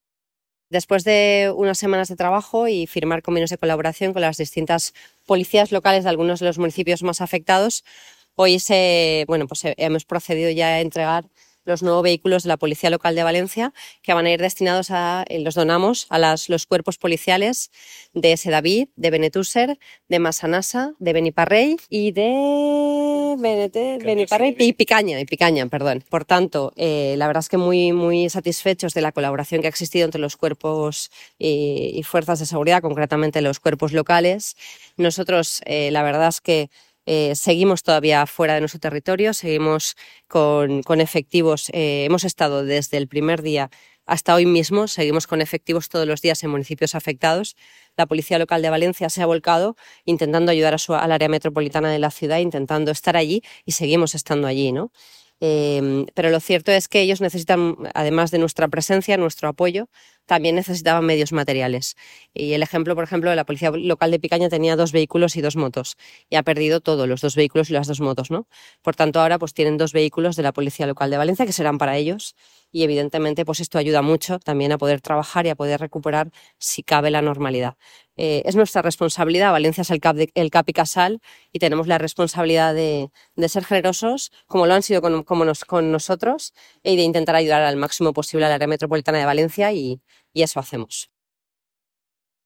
• La Central de la Policía Local de València ha acogido el acto de cesión de los vehículos.